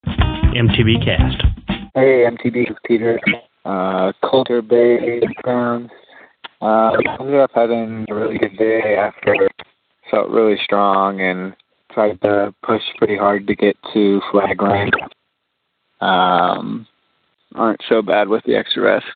Posted in Calls , TD17 Tagged bikepacking , calls , cycling , MTBCast , TD17 , ultrasport permalink